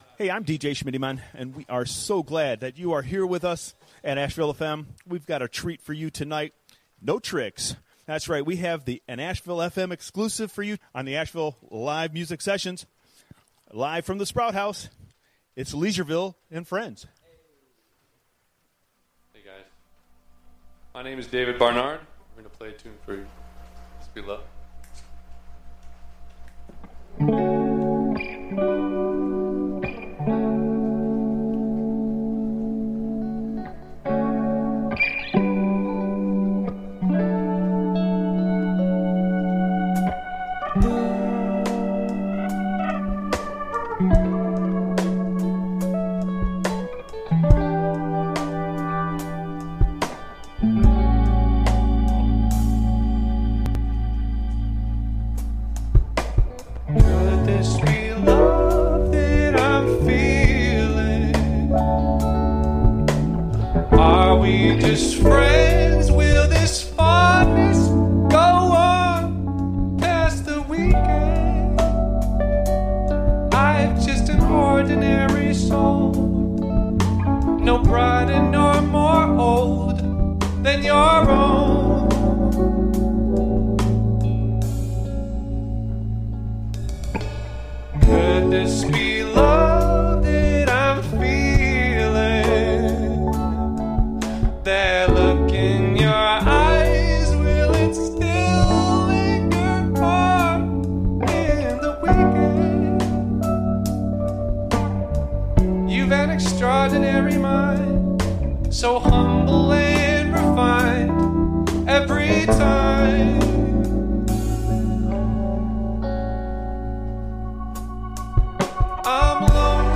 drums
soulful vibes